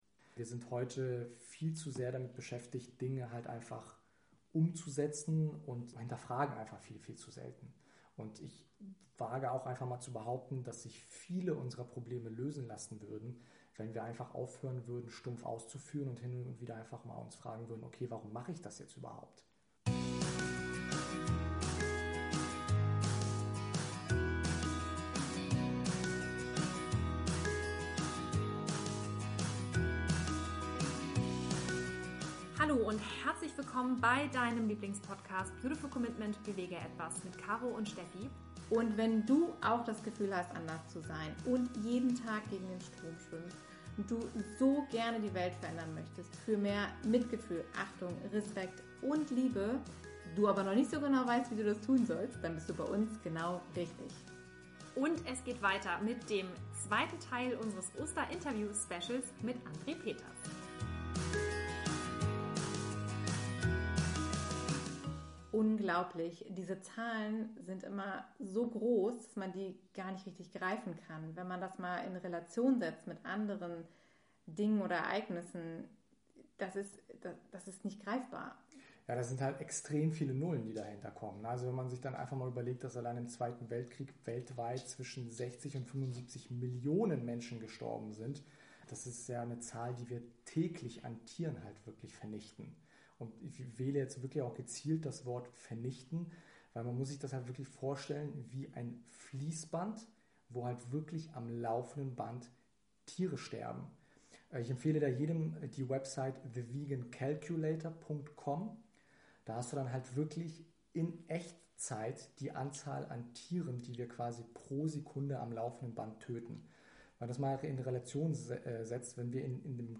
65 Oster - Interview Special
Tierrechtsaktivist und Tierrechtskommunikationstrainer